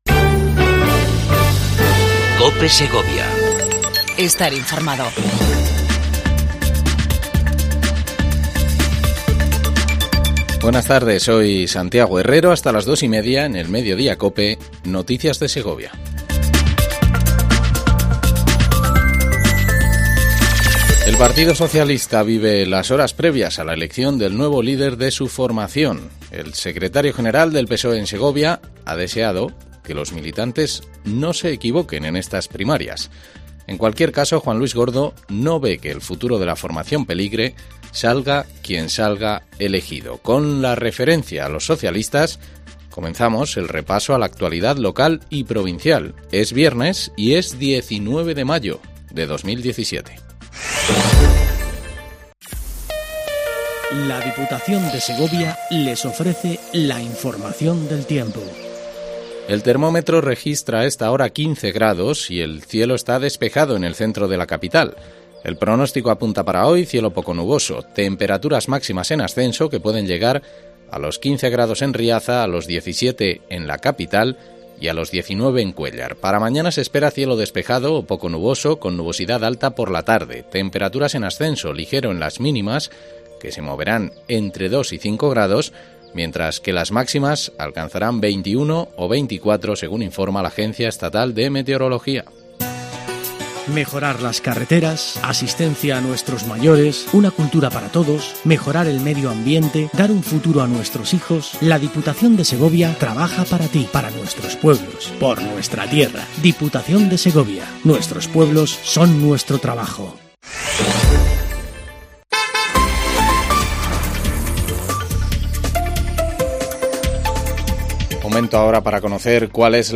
INFORMATIVO MEDIODIA COPE EN SEGOVIA 19 05 17